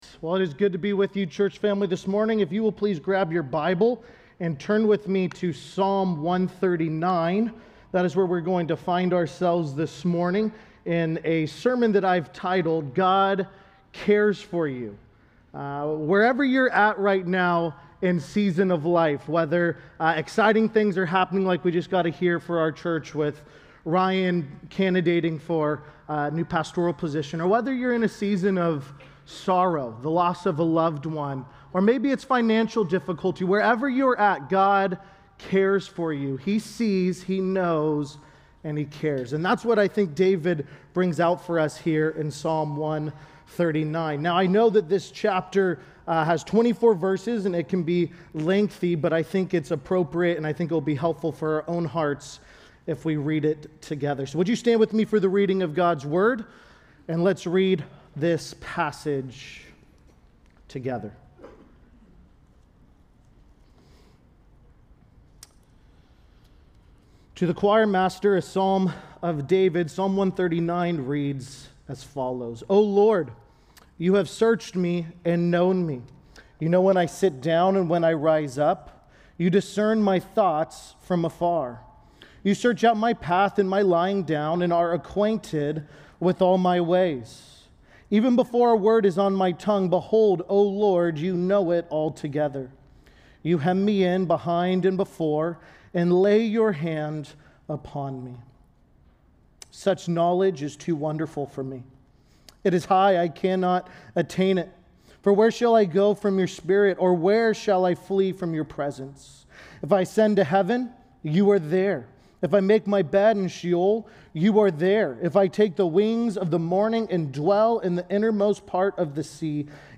Sermons from the Pulpit of Kindred Community Church